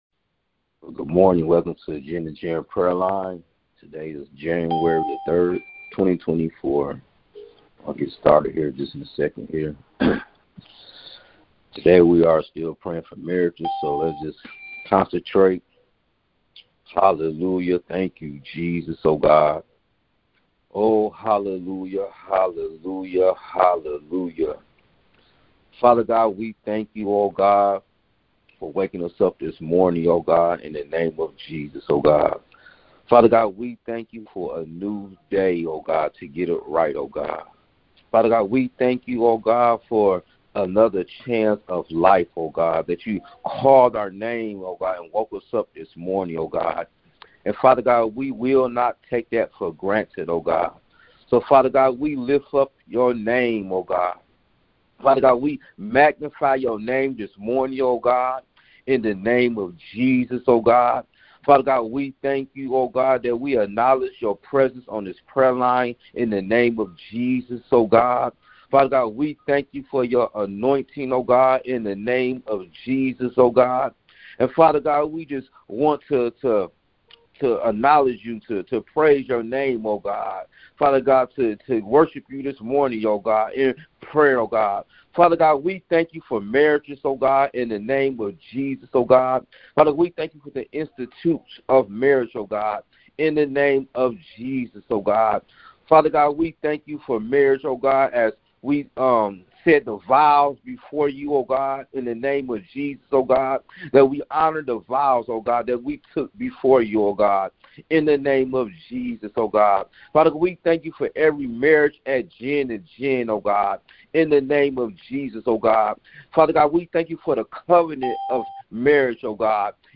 Prayers for married Ccouples taken from the weekly prayer conference line.